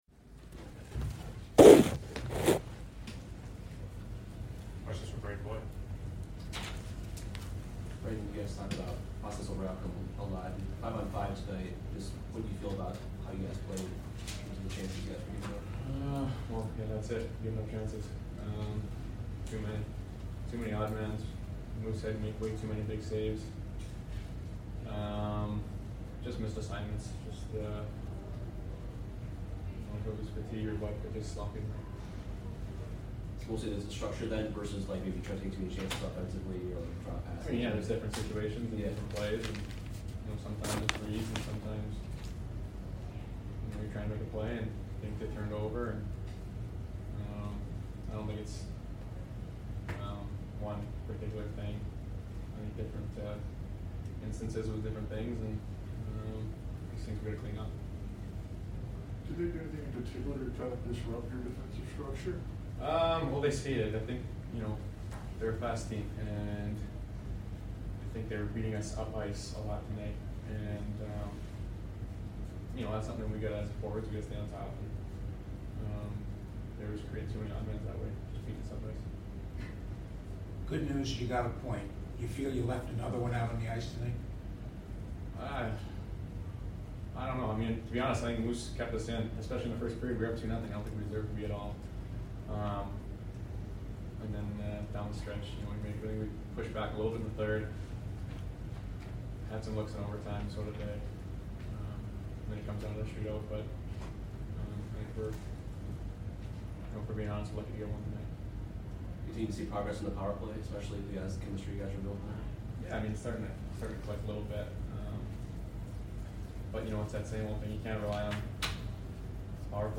Brayden Point Post Game Vs MTL 4 - 2-2022